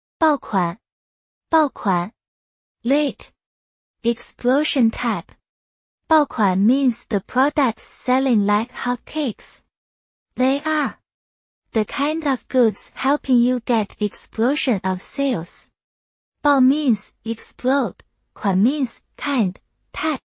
bào kuǎn